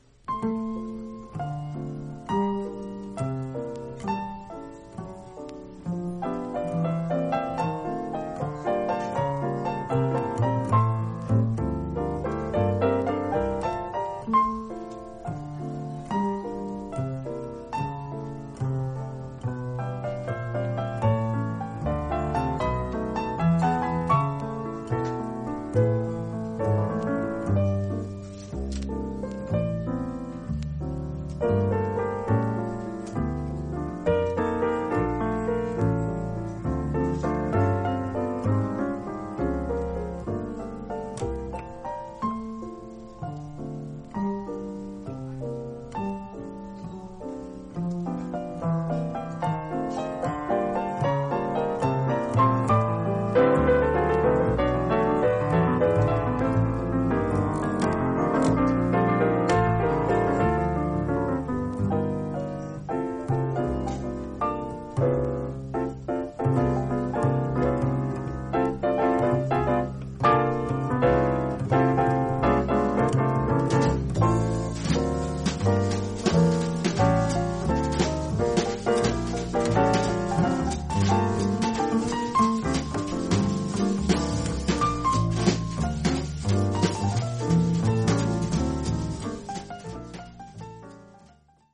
※A①中盤にキズがありその部分は少し薄くパチノイズが数回入ります（気づかない程度ではあります）。
実際のレコードからのサンプル↓ 試聴はこちら： サンプル≪mp3≫